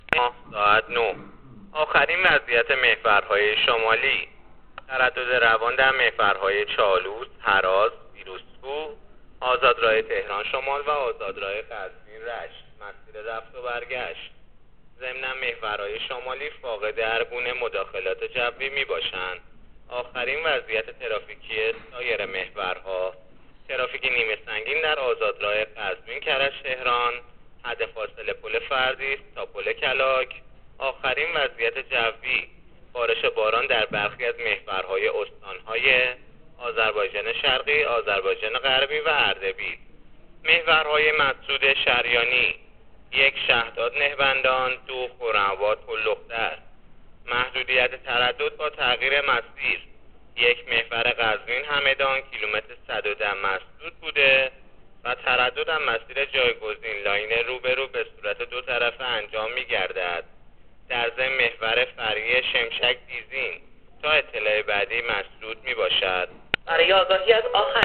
گزارش رادیو اینترنتی از وضعیت ترافیکی جاده‌ها تا ساعت ۹، هفدهم آبان